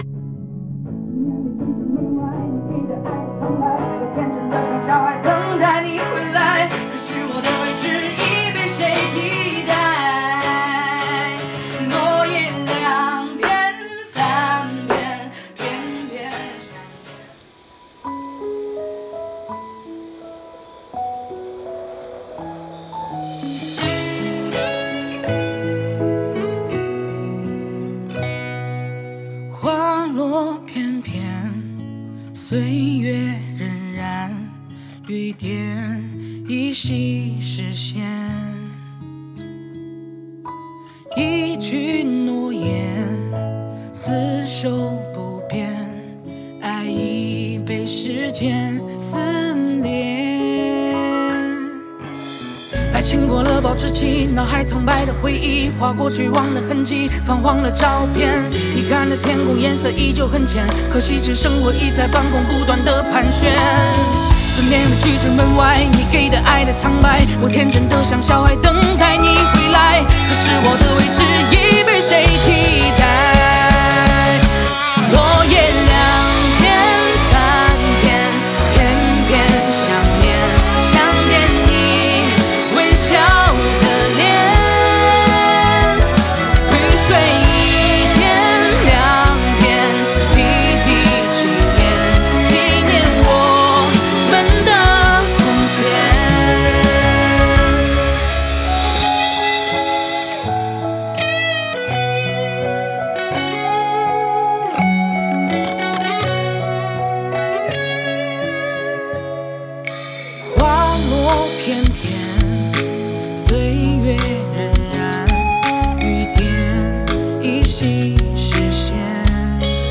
和声
吉他